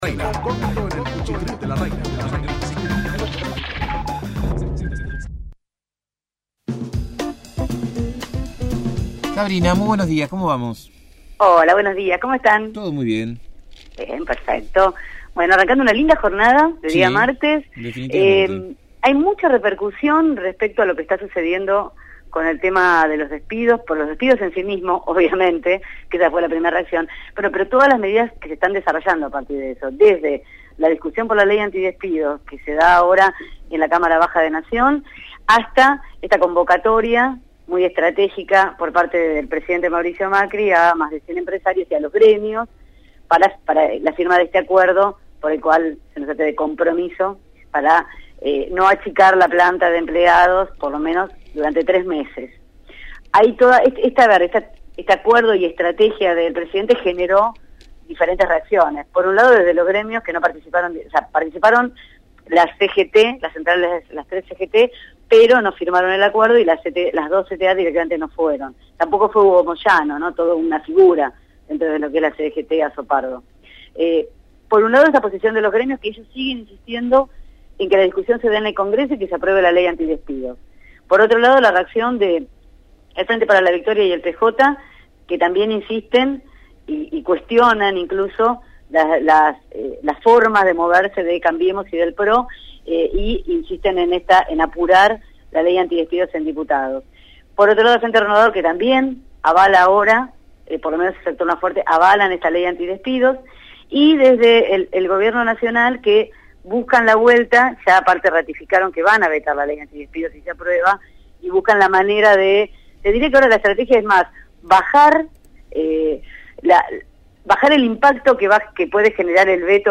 realizó su habitual informe sobre la actualidad política. En esta oportunidad se refirió a los despidos, al debate de la ley de la doble indemnizació, tanto en Nación como en provincia, para tratar de evitarlos y el acuerdo que firmó el presidente Mauricio Macri con un grupo de empresarios para intentar frenar la reducción de personal por 90 días.